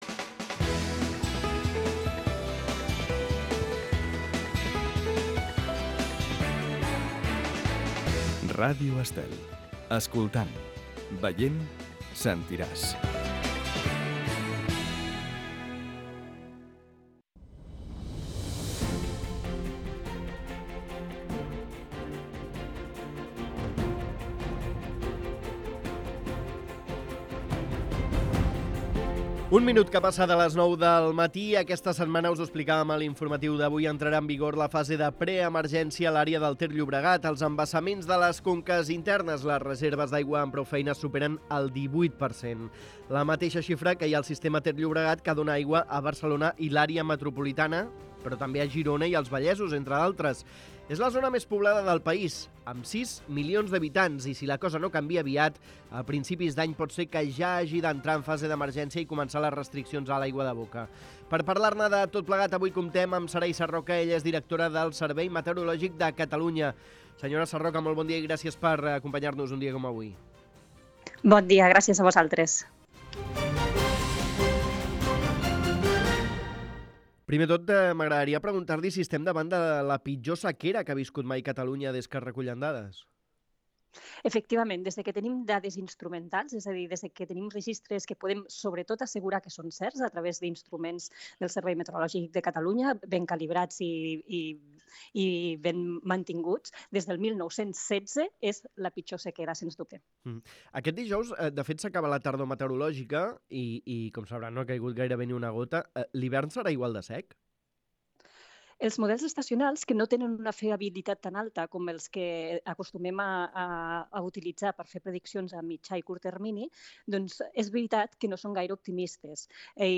La seva directora, Sarai Sarroca, així ho ha assegurat al programa La Caravana de Ràdio Estel.
Entrevista a Sarai Saroca, directora del Servei Meteorològic de Catalunya (Meteocat)